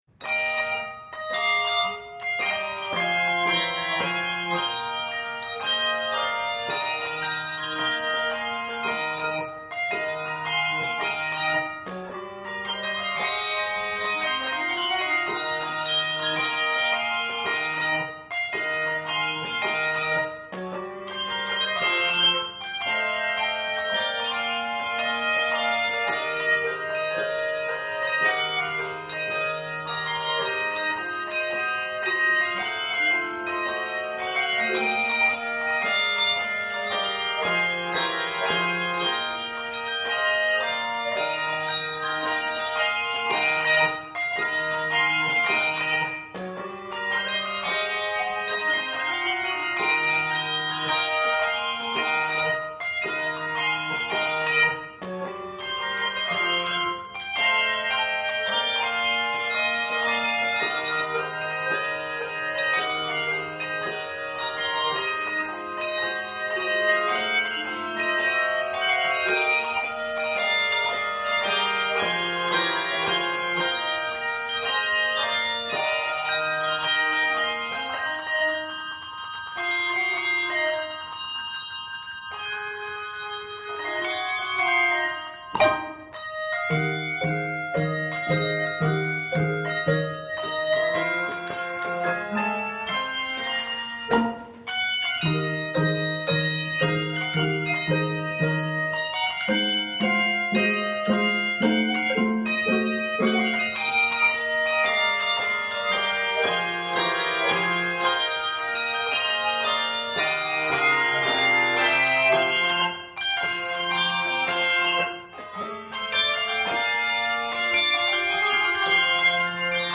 This joyous Christmas spiritual